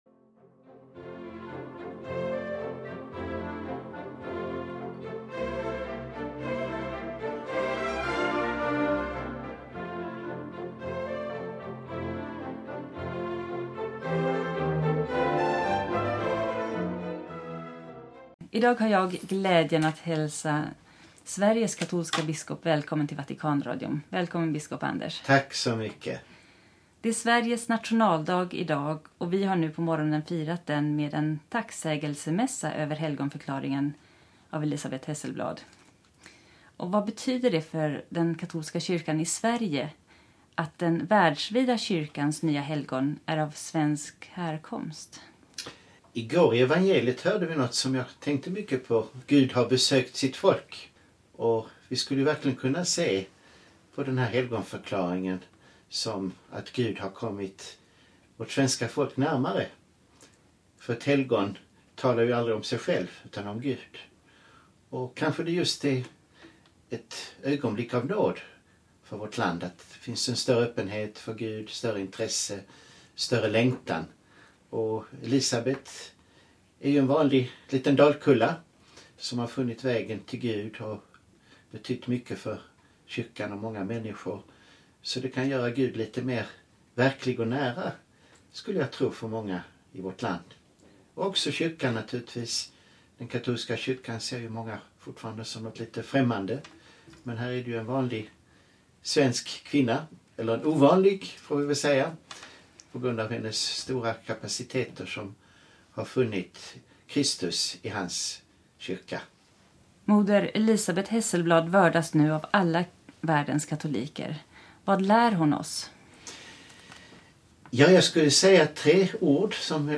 Efter helgonförklaringen fick Vatikanradion möjlighet att tala med Sveriges katolska biskop Anders Arborelius, som berättade om betydelsen av Moder Elisabeth Hesselblads helgonförklaring, för den katolska kyrkan i Sverige och i förhållande till den ekumeniska dialogen inför påven Franciskus resa till Lund i höst.